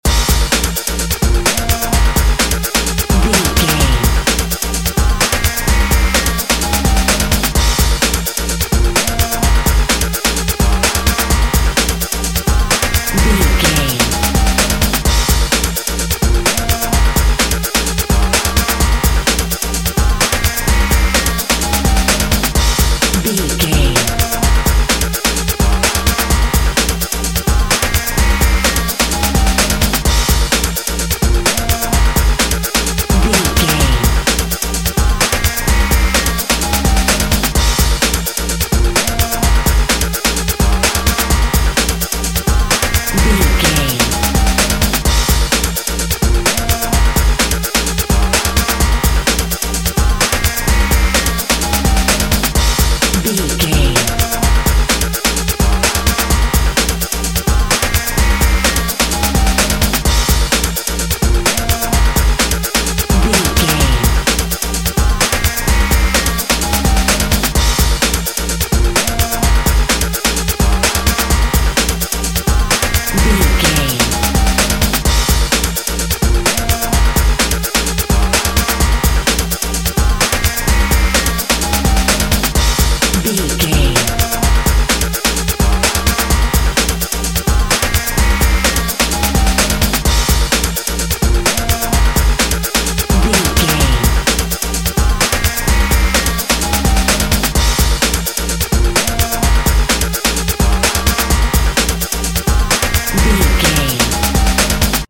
Epic / Action
Fast paced
Aeolian/Minor
B♭
Fast
intense
futuristic
energetic
driving
aggressive
dark
synthesiser
drum machine
breakbeat
power rock
synth drums
synth leads
synth bass